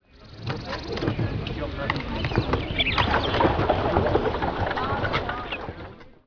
Ambient
city8.wav